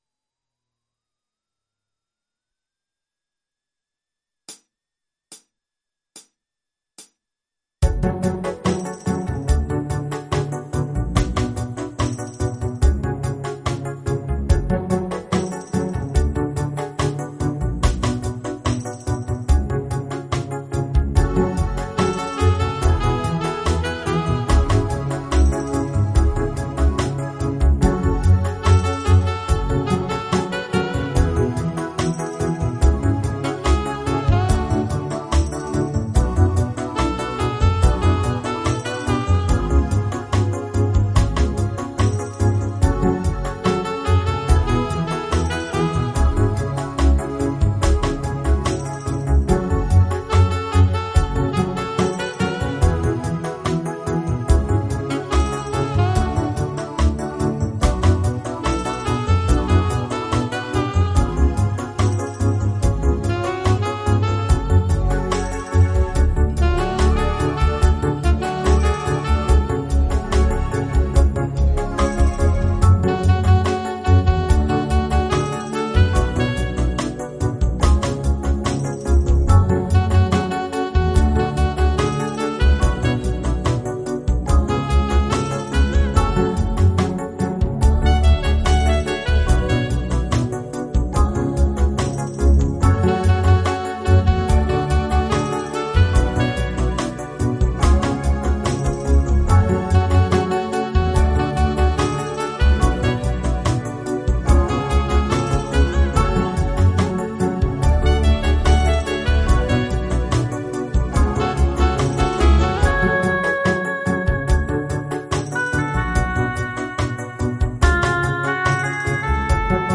multi-track instrumentale versie